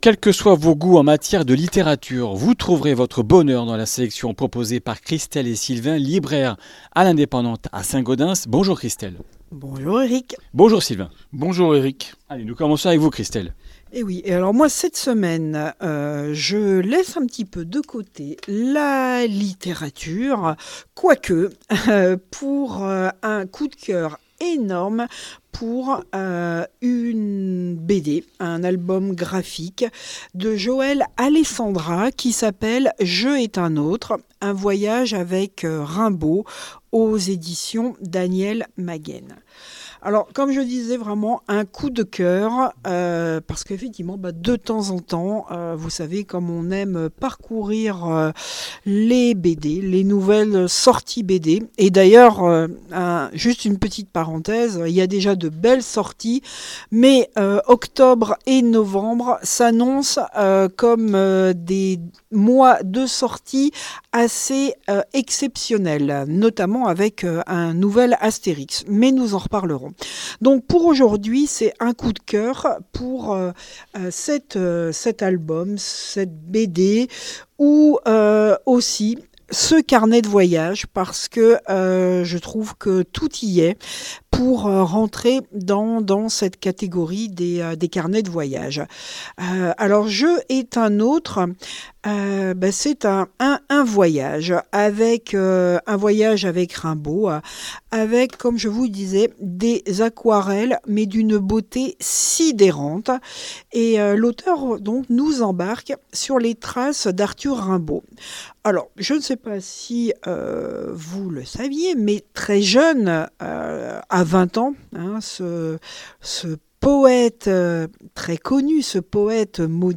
Comminges Interviews du 26 sept.